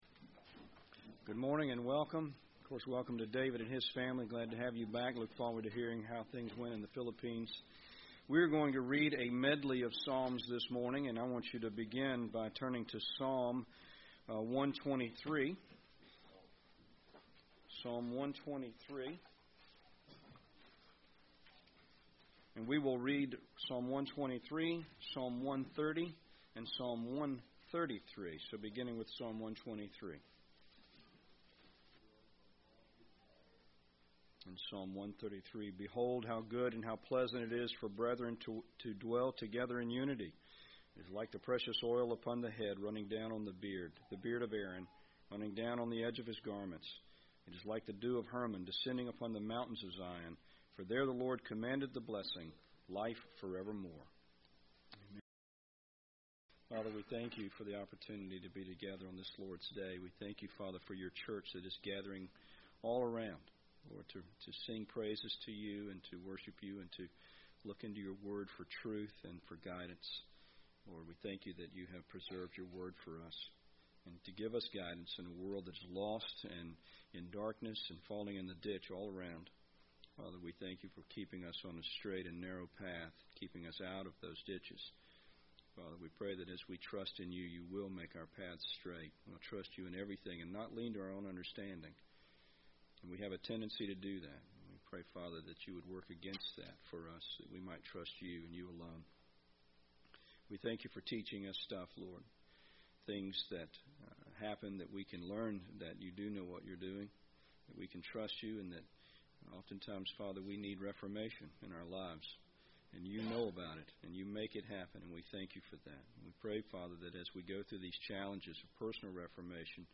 Missionary Report